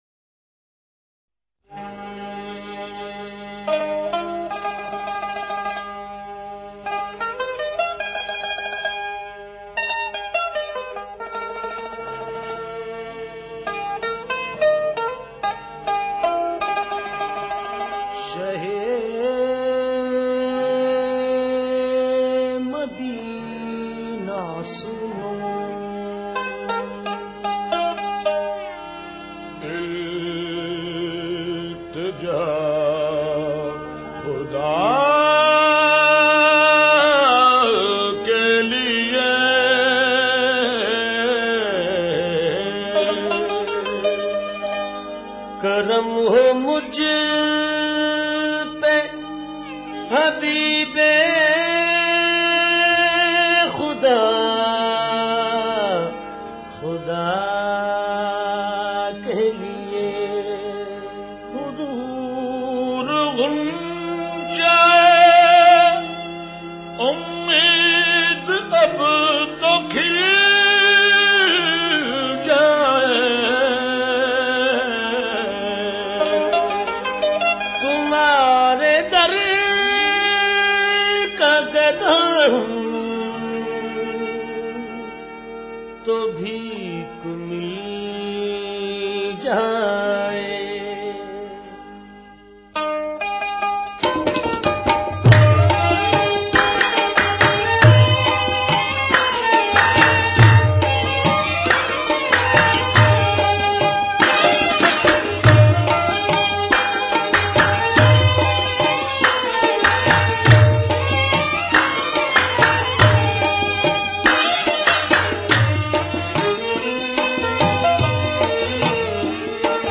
Qawwali